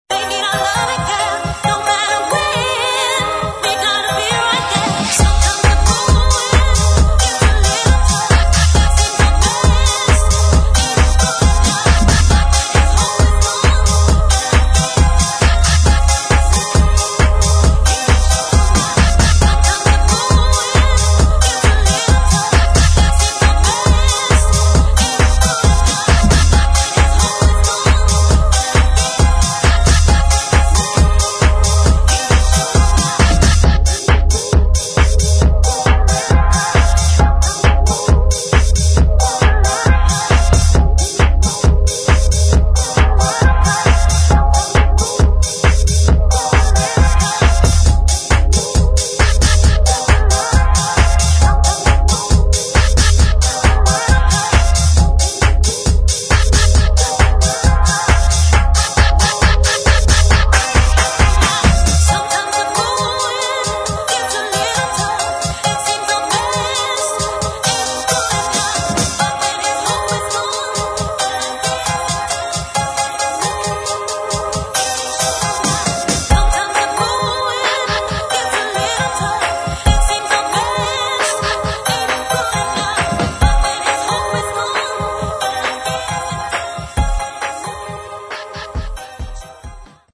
[ HOUSE | UK GARAGE ]